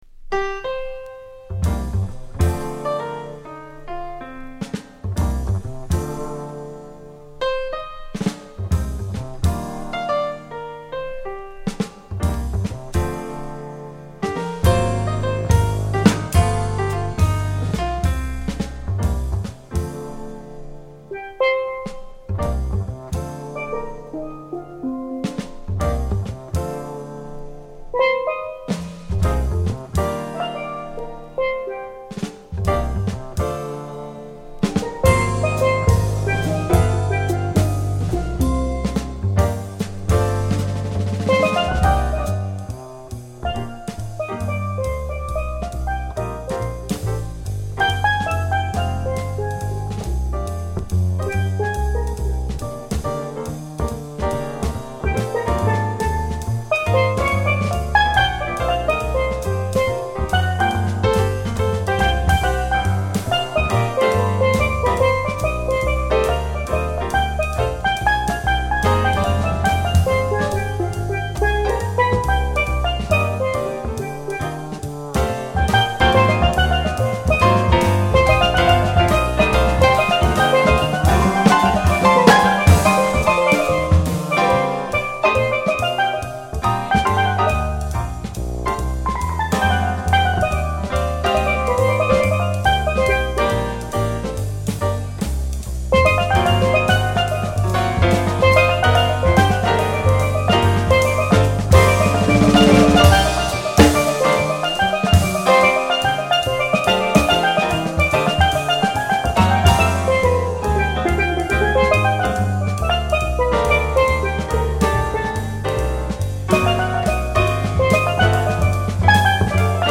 Jazz / Other sweden
ストレートなモダンジャズにスティール・パンを融合した特有のサウンドは中毒性高し。